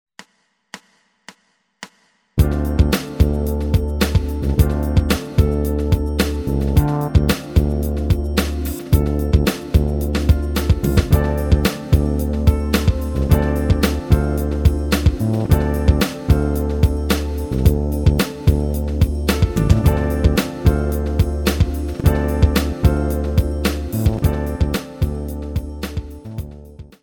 Negli esempi che andiamo a vedere ora proviamo a lavorare su di un pedale di Dm7.
base in formato mp3.
dm7base.mp3